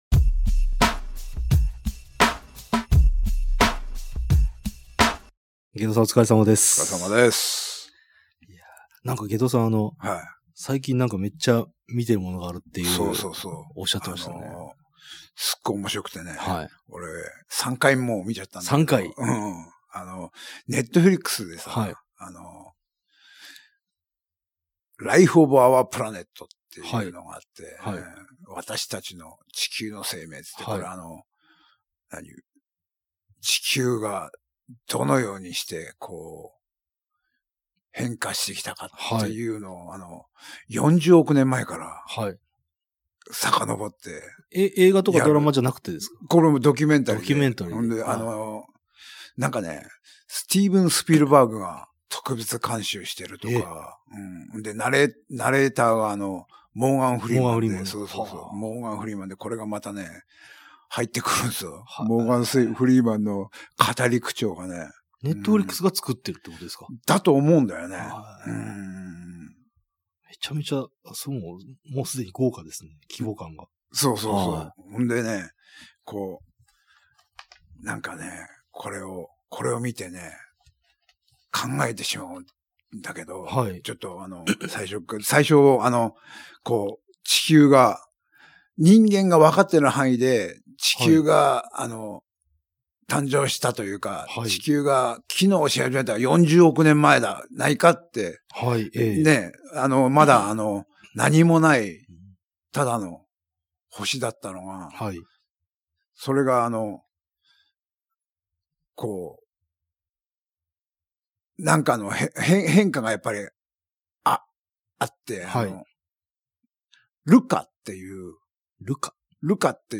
途方もない地球史をひもときながら、濃密な40分超のトークを展開。